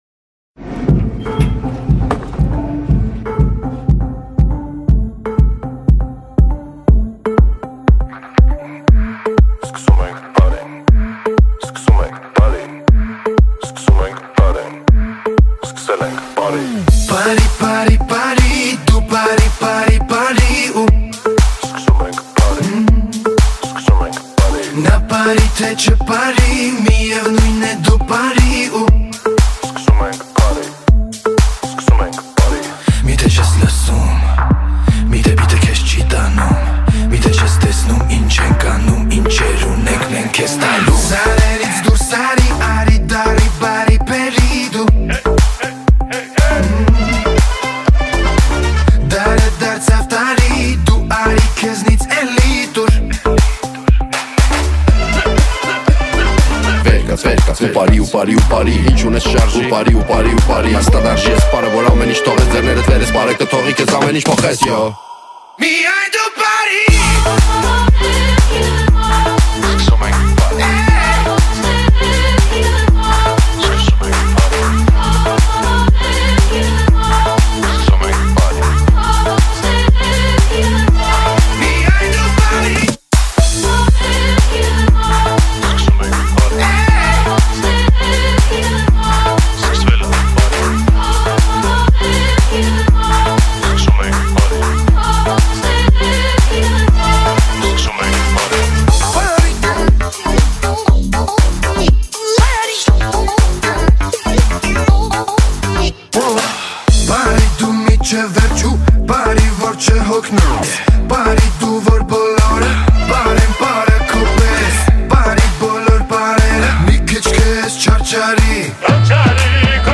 Танцевальный